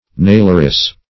Naileress \Nail"er*ess\, n. A woman who makes nails.